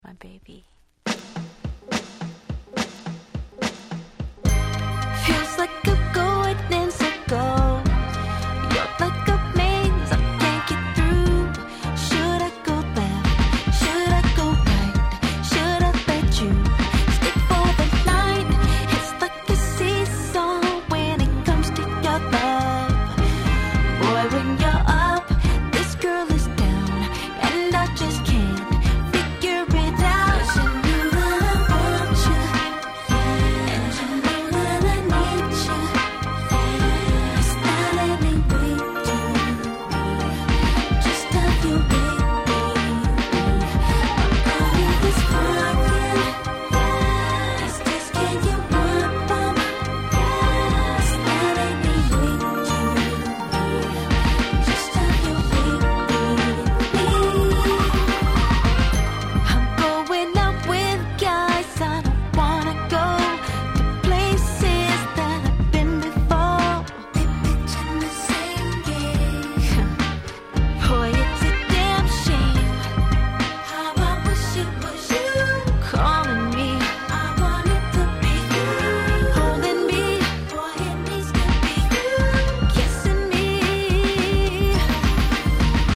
話はそれましたが、本作も所々Neo Soul風味を感じさせる良曲がちらほら。